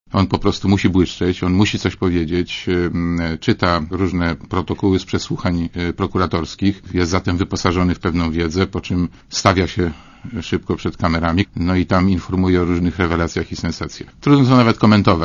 Z pobłażliwością i rozbawieniem patrzę na to, co robi Roman Giertych - powiedział w Radiu ZET Marek Borowski (SdPl).
Posłuchaj komentarza Marka Borowskiego